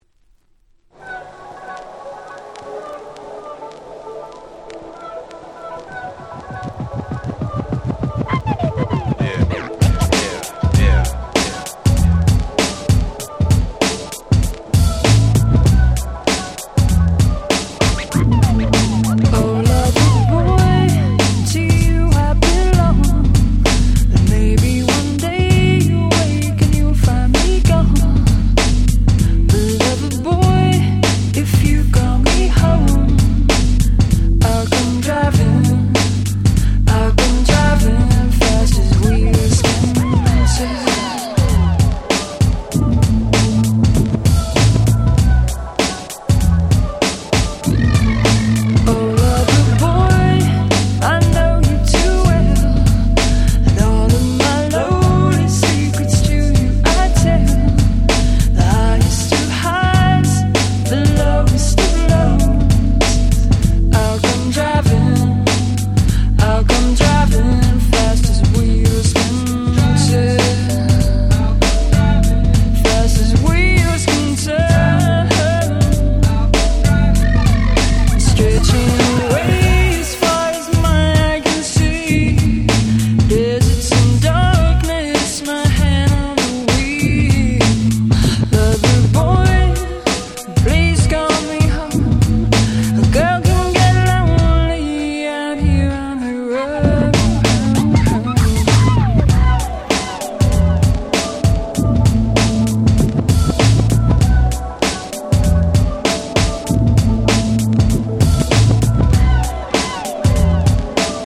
96’ Nice UK R&B / Vocal House !!